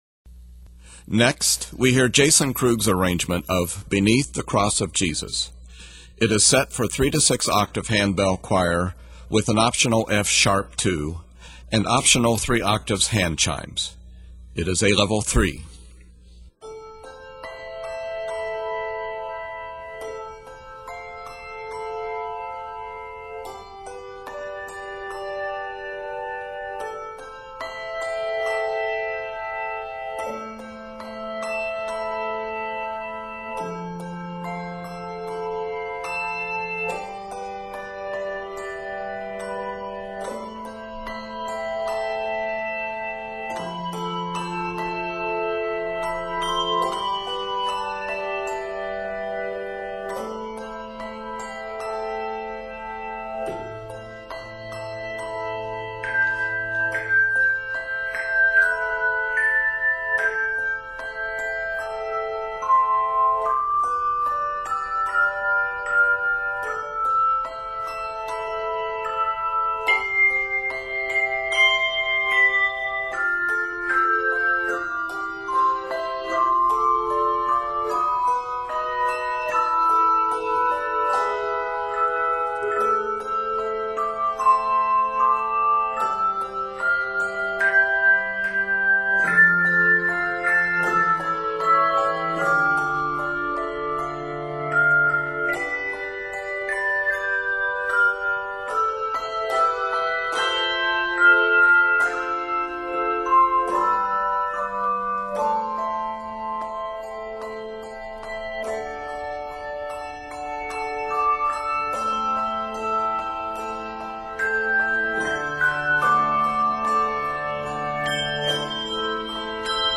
Octaves: 3-6